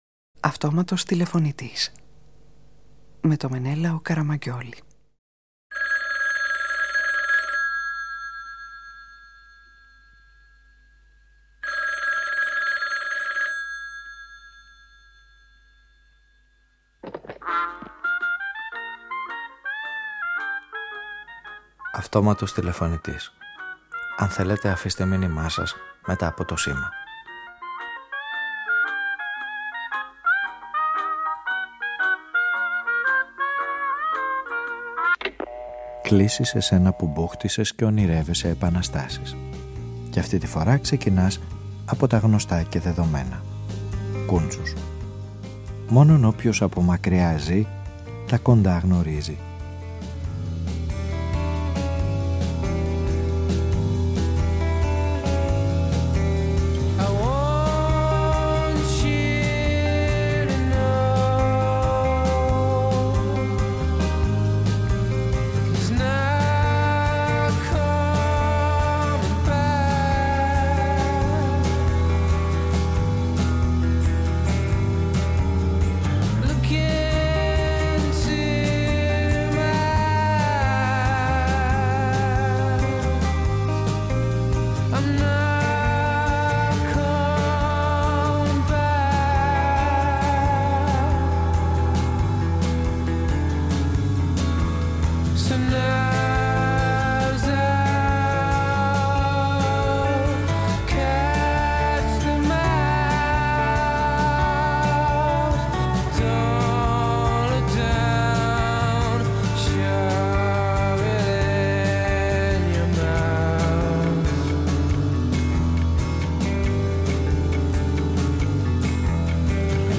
Όσοι μπούχτισαν να ονειρεύονται επαναστάσεις γίνονται οι ήρωες αυτής της ραδιοφωνικής ταινίας που κατέφυγαν στον Κούντσους σαν να να είναι φίλος παρών, υπομονετικός κι έτοιμος να δώσει απαντήσεις σε όσα τους πνίγουν καιρό τώρα. Οι πλάνες της εγγύτητας, τα ξεγελαστικά τερτίπια της συναναστροφής, οι θεοί και τα υποκατάστατά τους γίνονται τα συστατικά ενός μηνύματος που οδηγεί στη διαρκή ανάγκη μιας εξέγερσης που διαρκεί και δικαιώνει μέχρι τέλους.